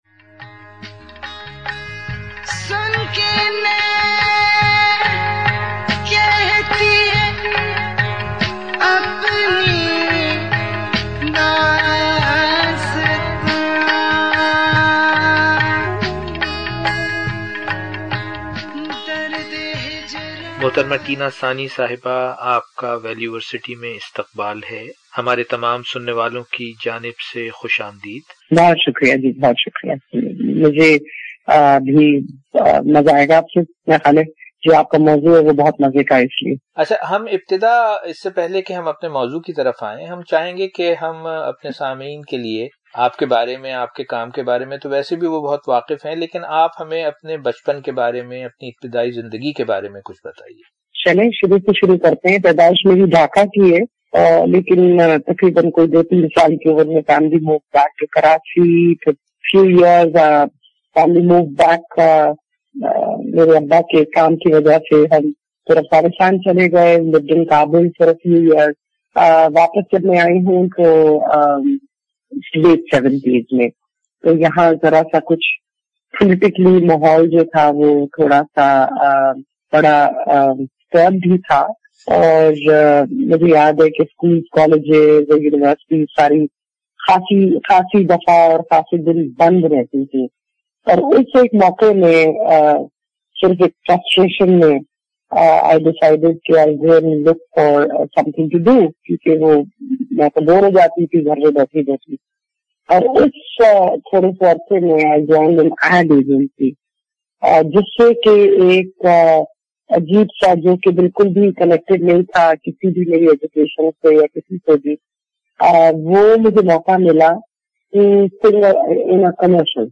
Part 1 A journey of self exploration. Conversation with famous Pakistani singer Tina Sani